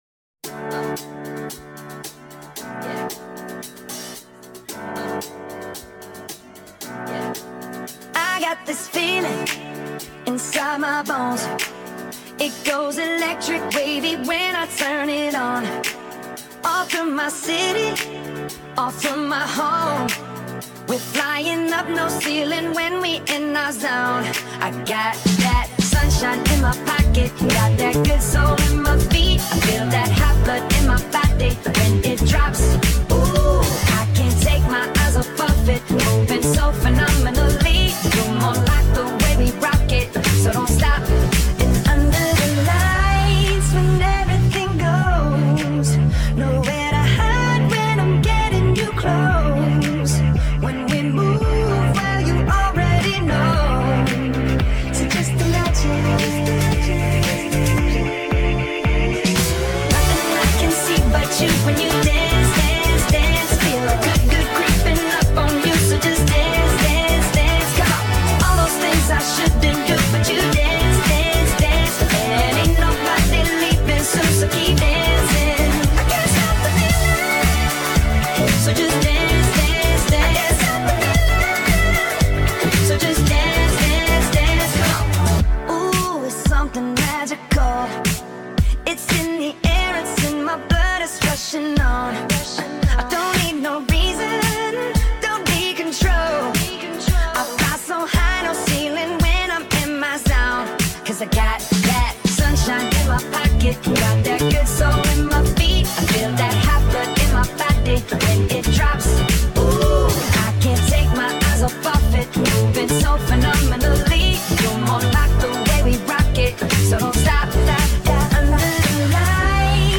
in our key of D (up a whole step)